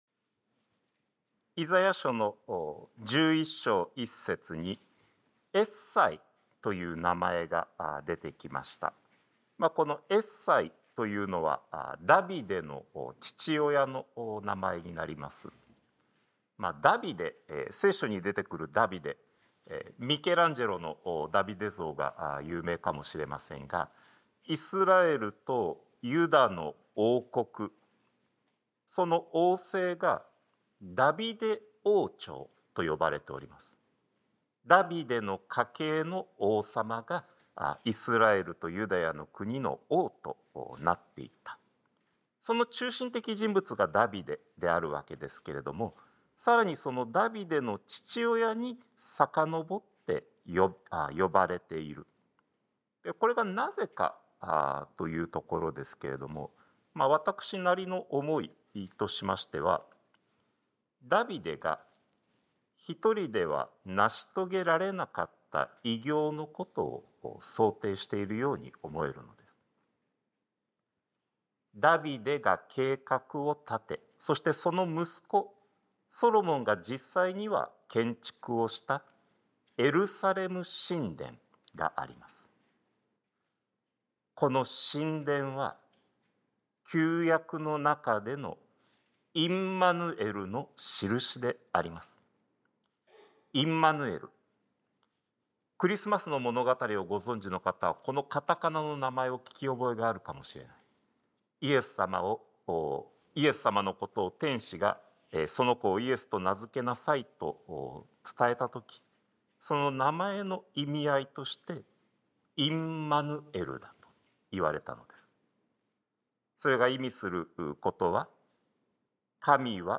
sermon-2024-12-22